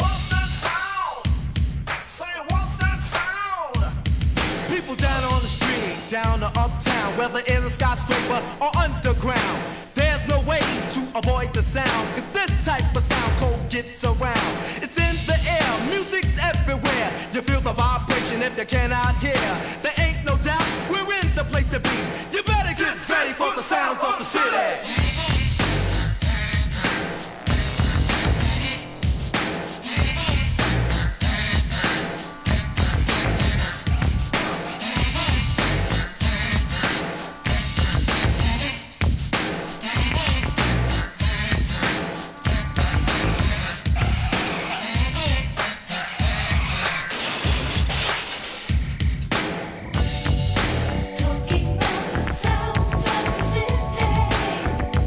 OLD SCHOOL HIP HOP